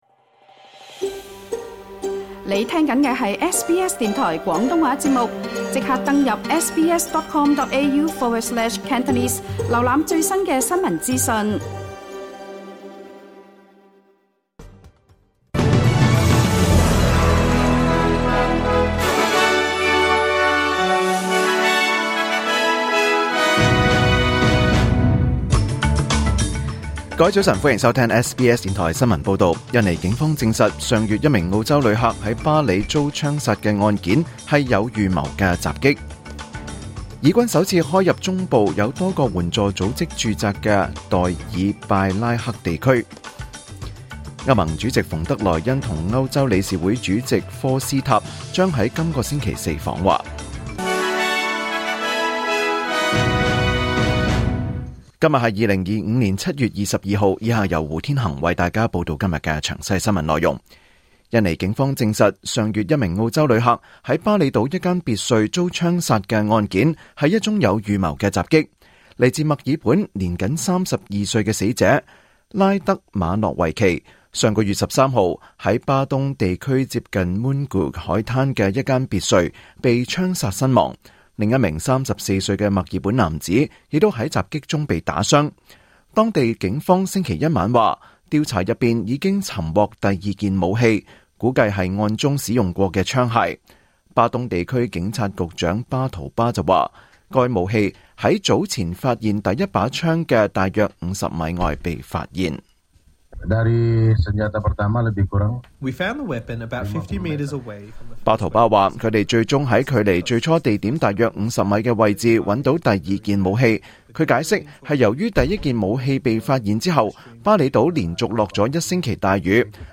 2025年7月22日SBS廣東話節目九點半新聞報道。